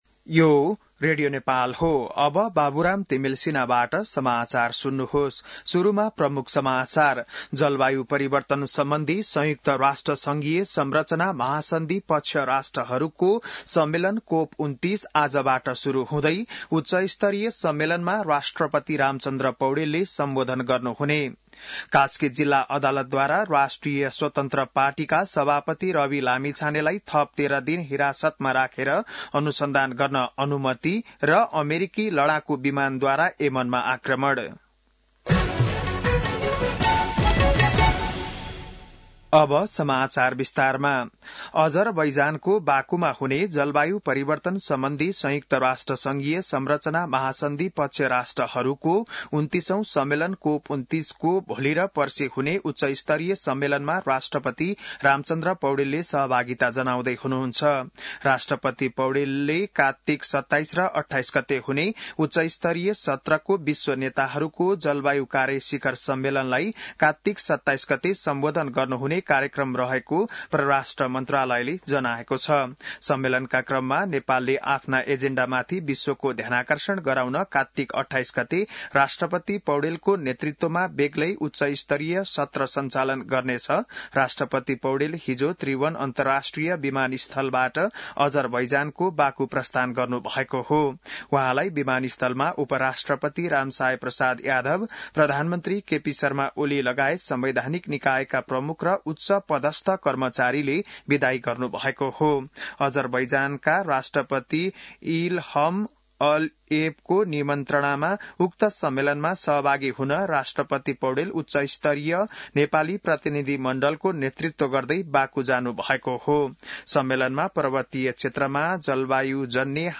बिहान ९ बजेको नेपाली समाचार : २७ कार्तिक , २०८१